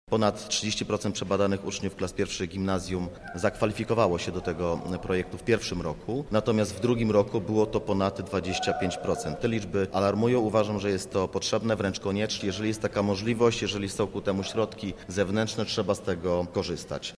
Jak mówi Edwin Gortat, wójt gminy Wólka, ważne jest, by regularnie sprawdzać nasze zdrowie.
Konferencja PoZdro